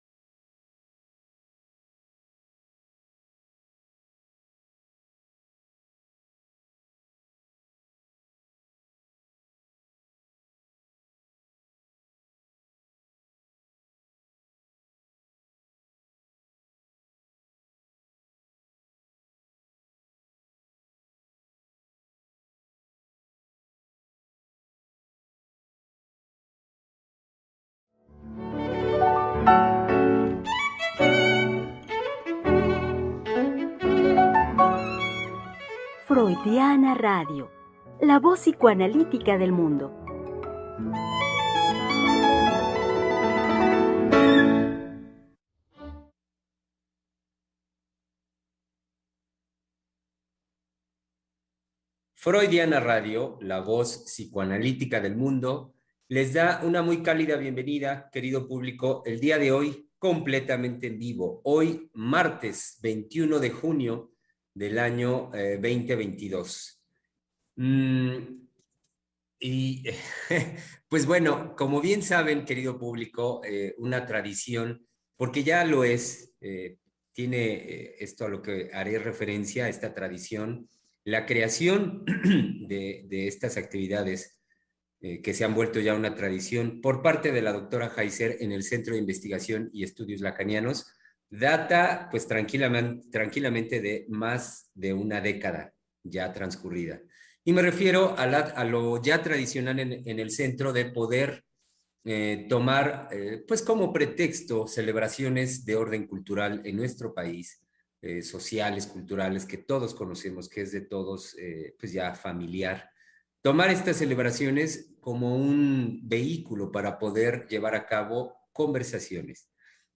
Programa transmitido el 21 de junio del 2022.